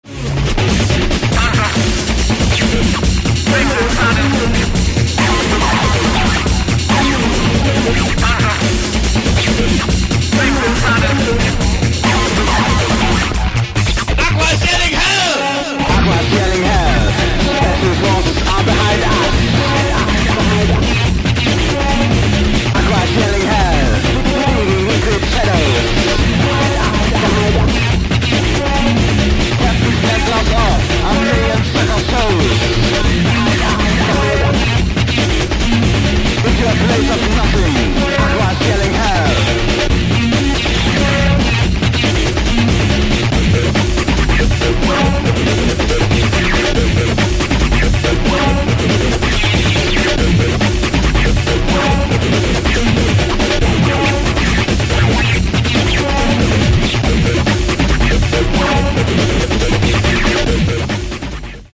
"The Prodigy", a hard techno style with breakbeat
The tracks needed to feel aggressive